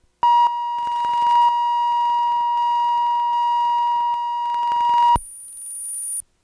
【Auto-BLE信号音】